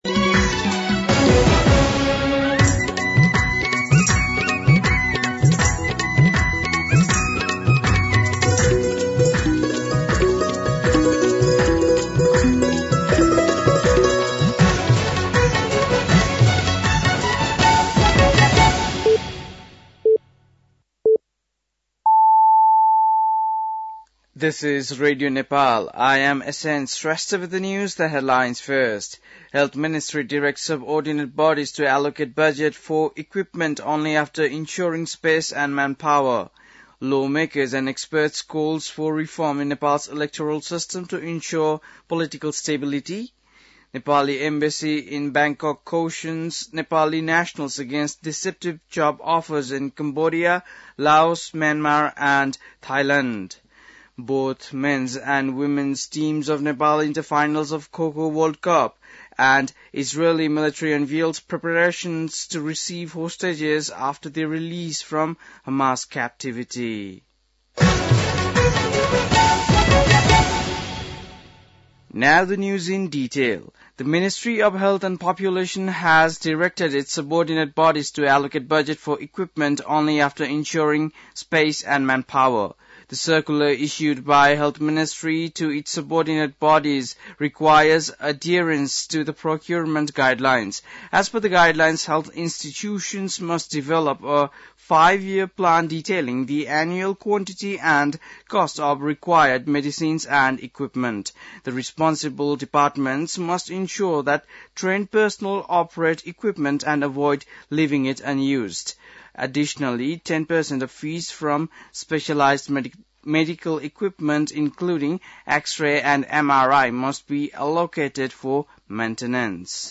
बेलुकी ८ बजेको अङ्ग्रेजी समाचार : ६ माघ , २०८१
8-PM-English-News-10-5.mp3